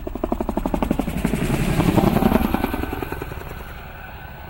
звук лопастей вертолета.ogg